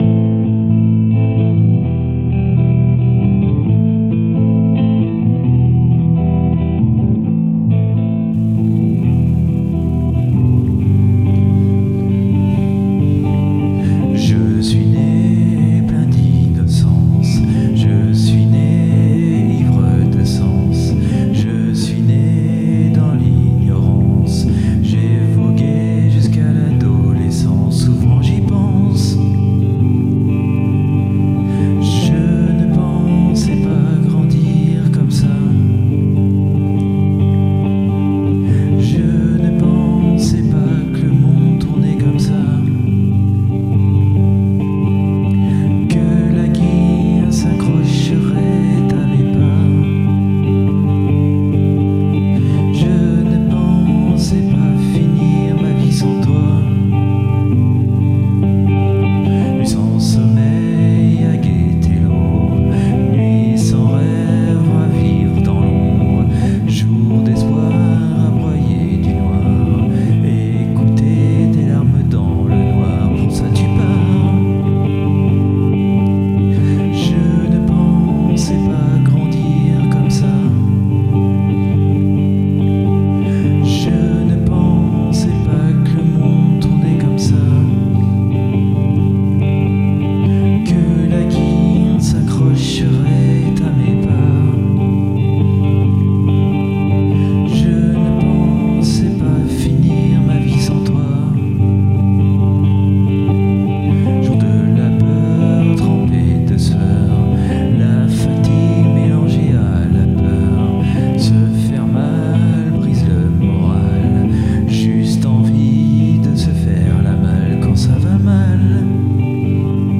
Musique pour Soigner les Peines de Cœur et Avancer
composition instrumentale mélancolique et apaisante